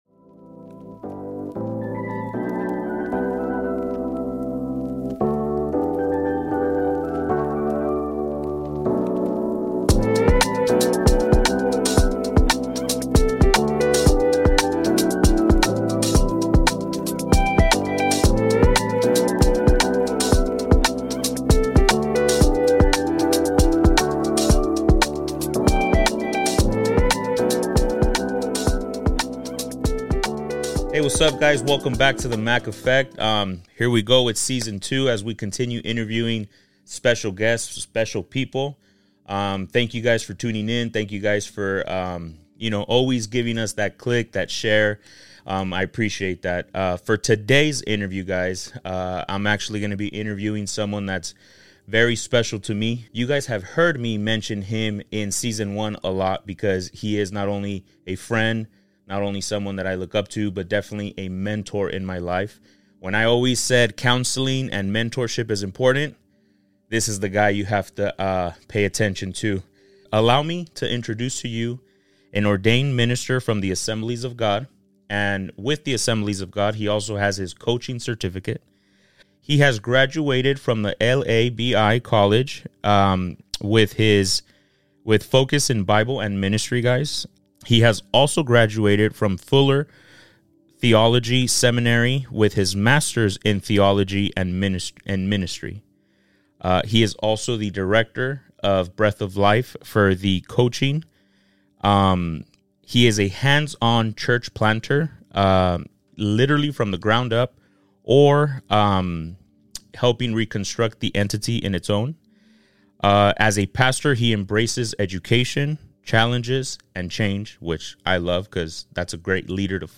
Interview
Listen to two men share deep pains and hurts with hearts that have gone thru a process and where healing is very much intentional.